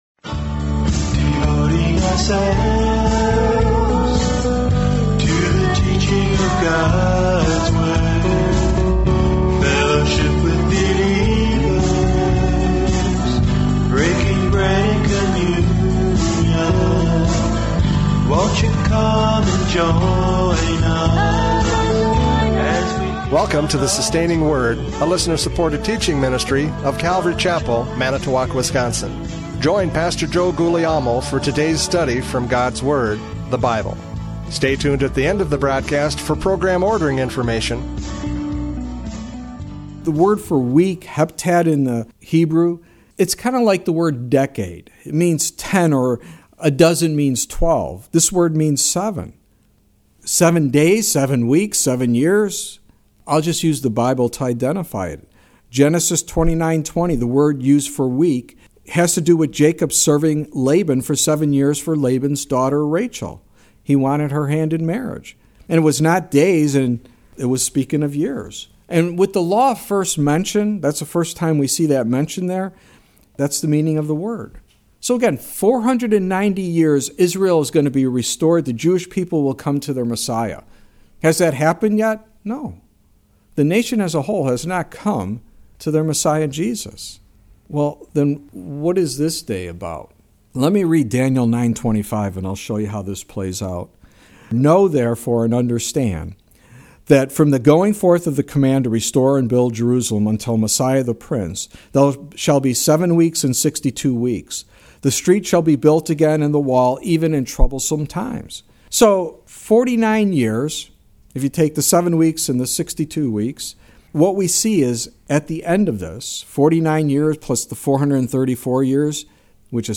John 12:12-22 Service Type: Radio Programs « John 12:12-22 The Triumphant Entry!